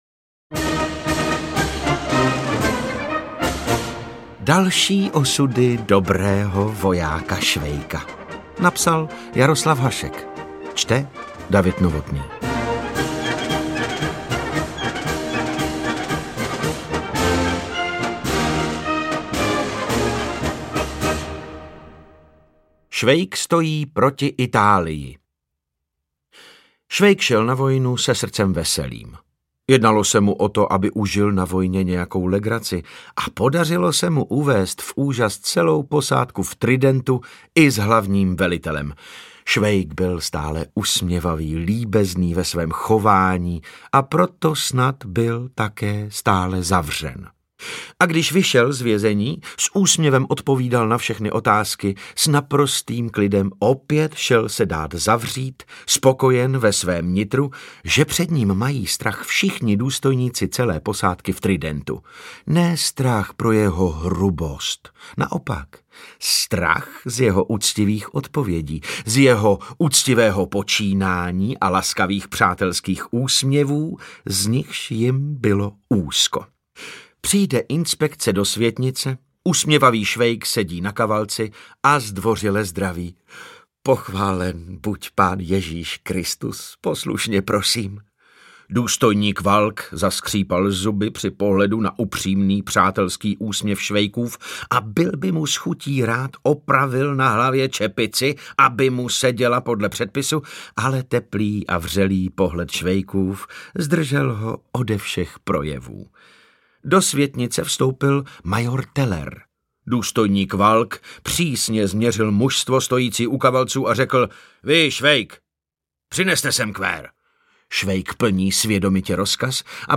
• AudioKniha ke stažení Hašek: Další osudy dobrého vojáka Švejka
Interpreti:  David Novotný, David Novotný, David Novotný
Šest textů o dobrém vojáku Švejkovi z pera Jaroslava Haška, které vznikly ještě před slavným románem. Tyto jiné a překvapivé osudy dobrého vojáka Švejka s gustem načetl herec David Novotný.